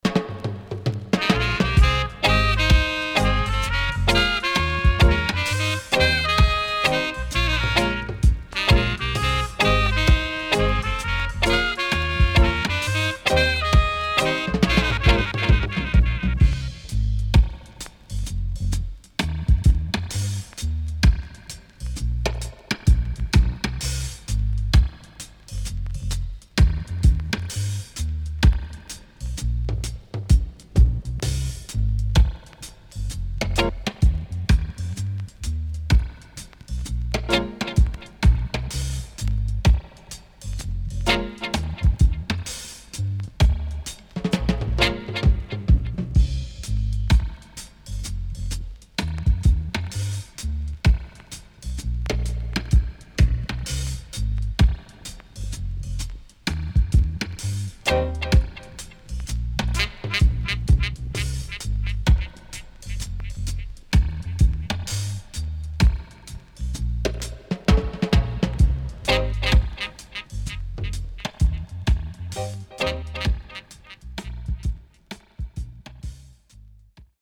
SIDE A:少しチリプチノイズ入りますが良好です。